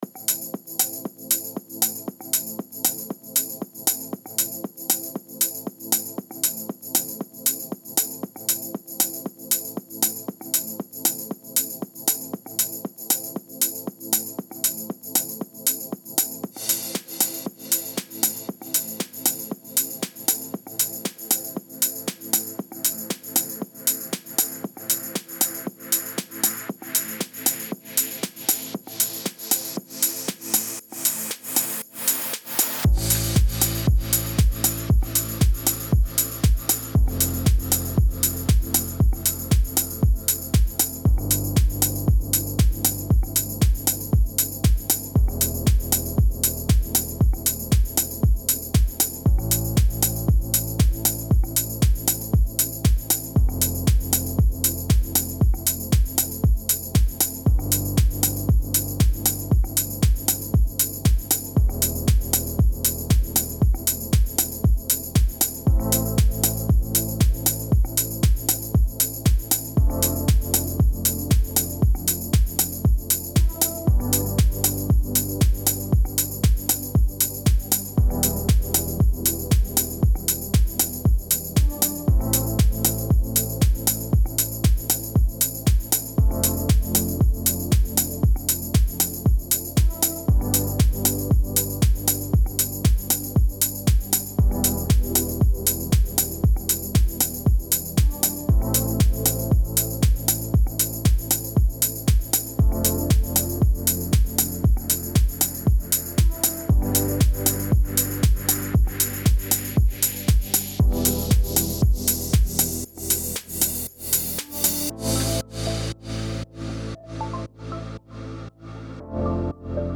موسیقی بی کلام چیل‌اوت
موسیقی بی کلام ریتمیک